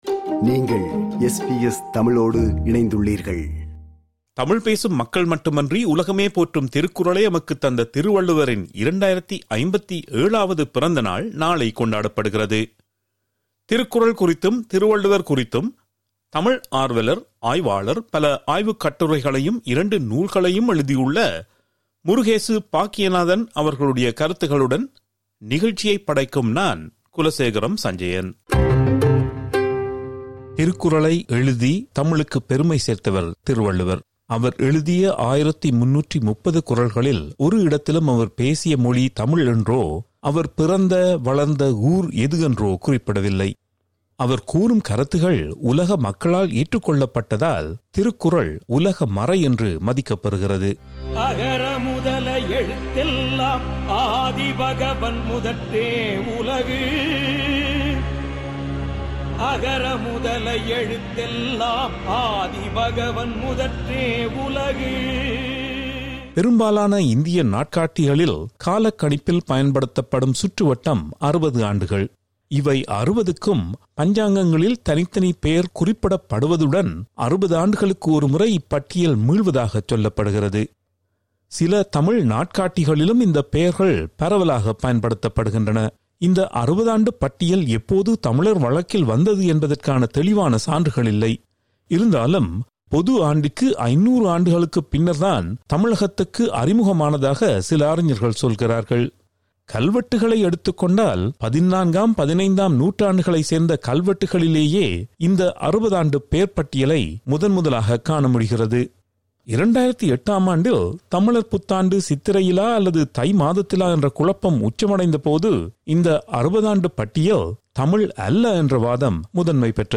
[இது ஒரு மறு ஒலிபரப்பு.]